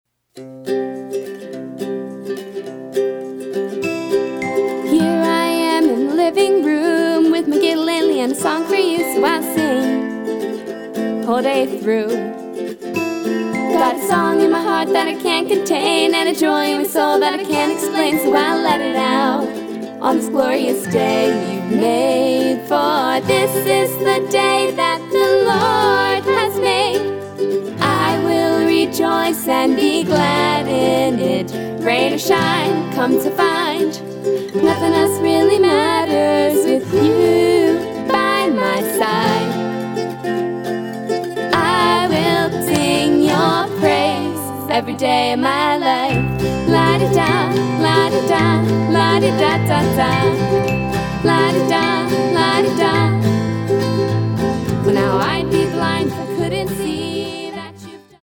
eclectic, contemporary Bible verse songs